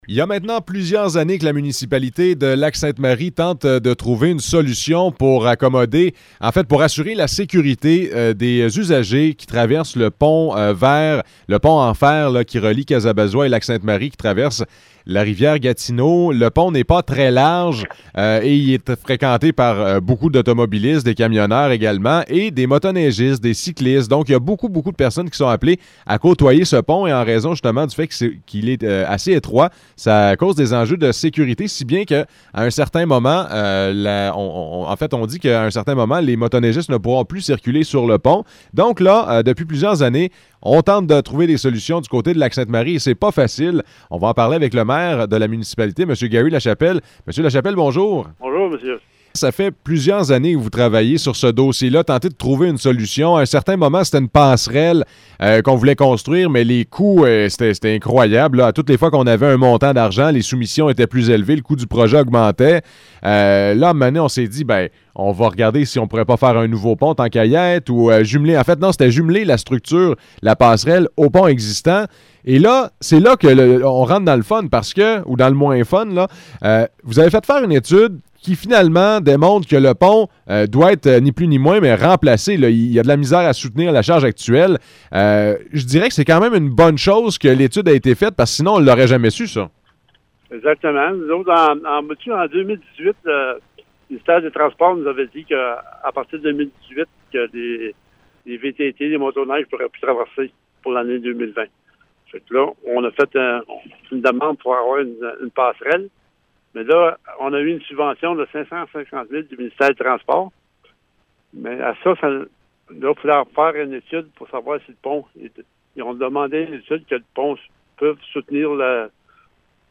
Entrevue avec Gary Lachepelle, maire de Lac-Sainte-Marie
Entrevues